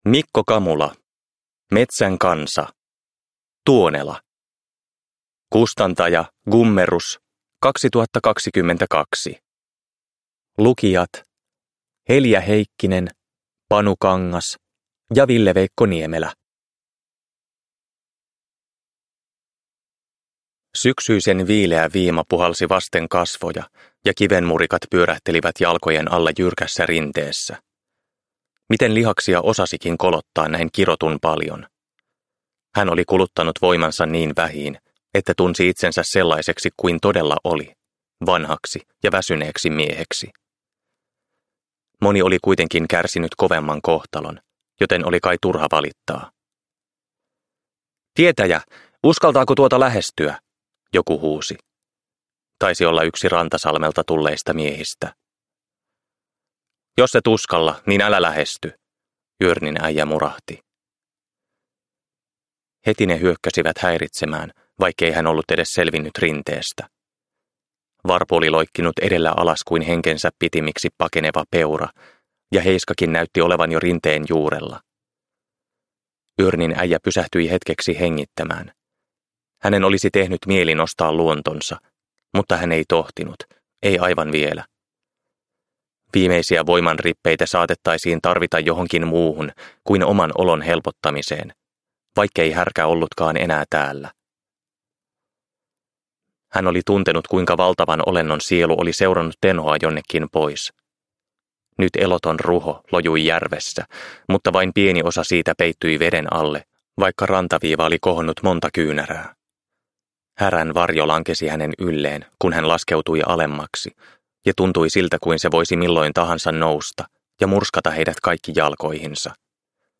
Tuonela – Ljudbok – Laddas ner